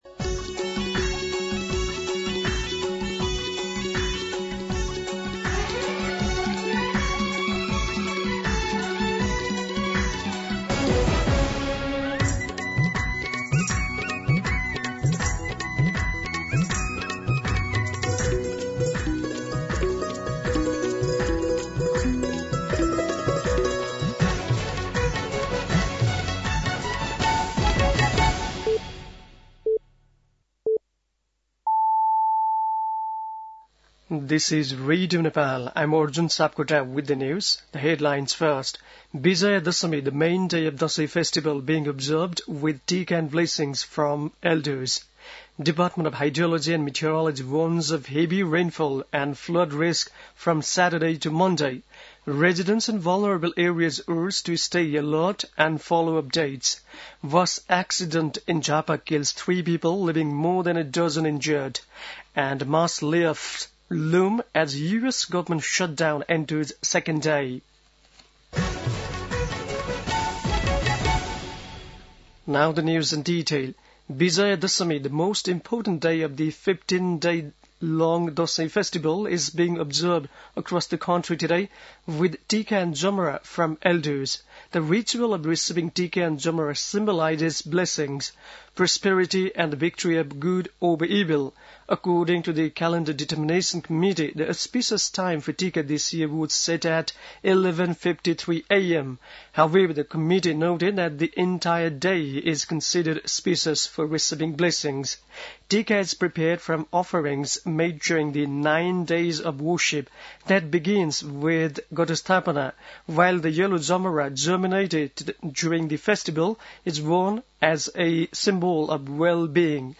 दिउँसो २ बजेको अङ्ग्रेजी समाचार : १६ असोज , २०८२
2-pm-English-News.mp3